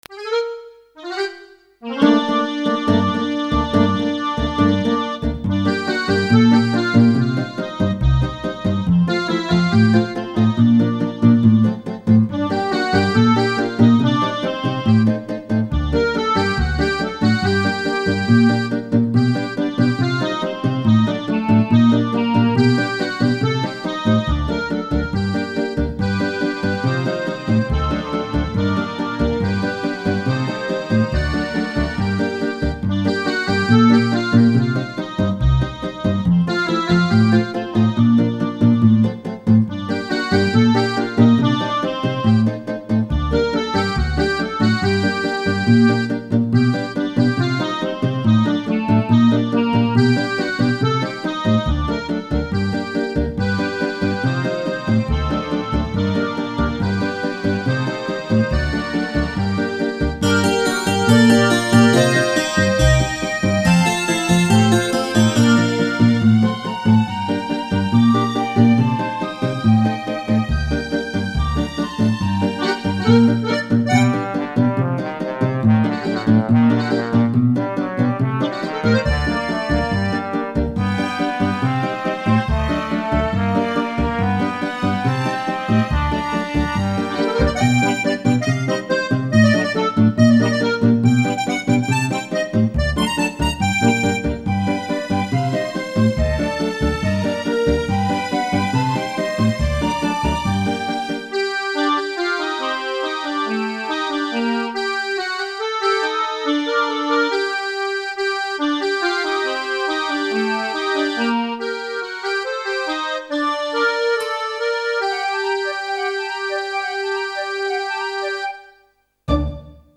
Эта точно такая, ток темп чуток помедленнее